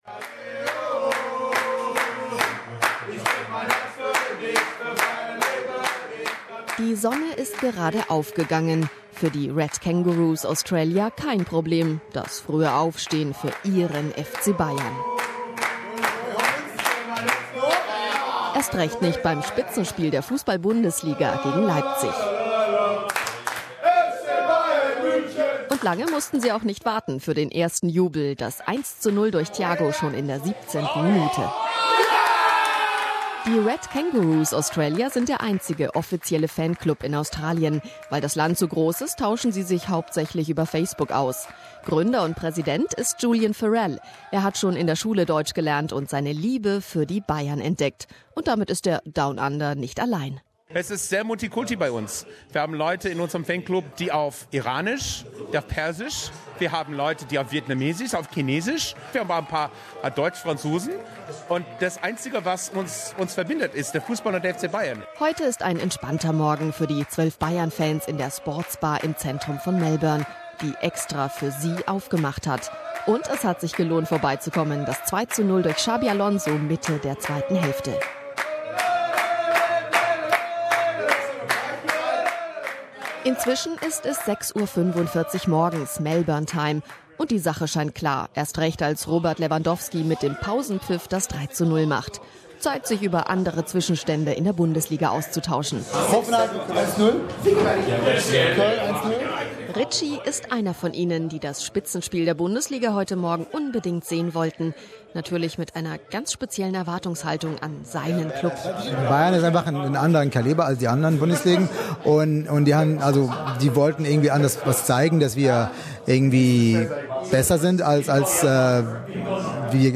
Die Bundesliga geht jetzt in die Winterpause - zuvor gab es noch das große Highlight, denn die Bayern trafen auf den Kometen-Aufsteiger RB Leipzig. Der Sieger im Schlager, der deutsche Rekordmeister, wurde auch in Melbourne gefeiert - in einer Kneipe kurz nach Aufgang der Sonne - noch vor der regulären Arbeitszeit.